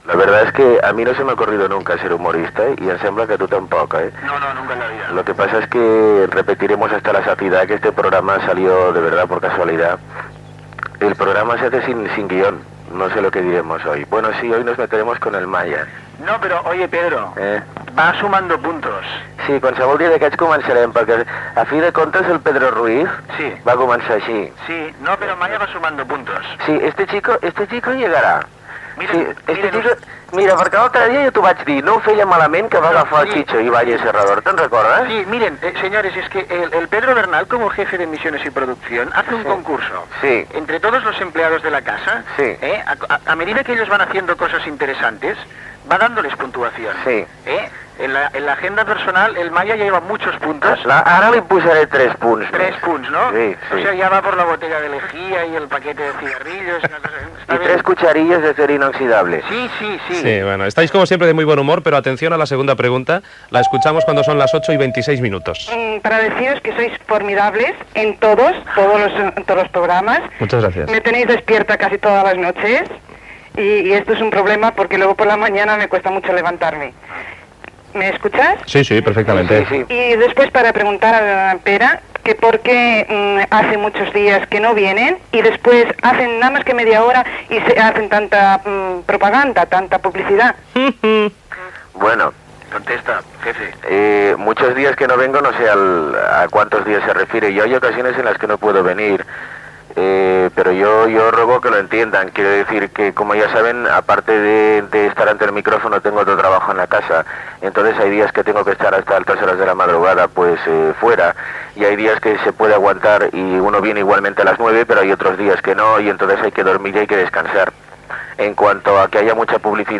Preguntes dels oïdors.
Entreteniment